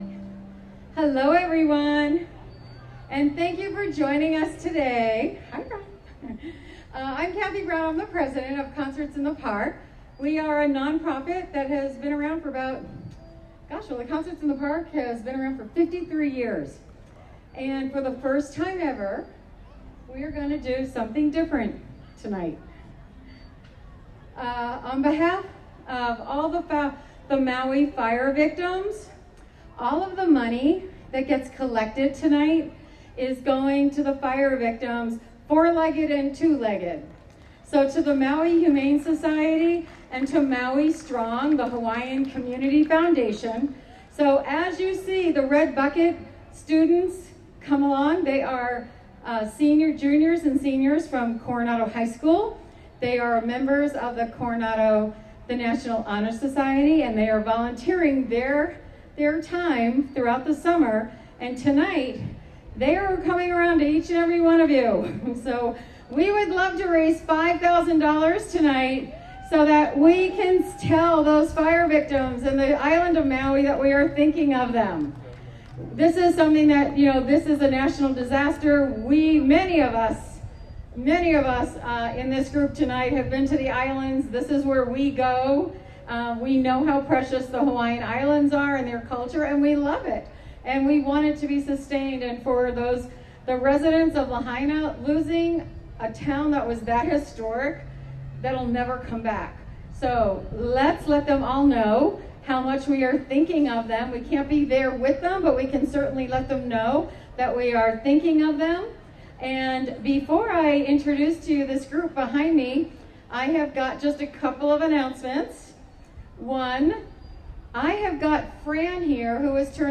The Coronado Ukulele Club performed the Maui/Lahaina Benefit Concert August 13, 2023 at the most excellent Spreckels Park in Coronado, California.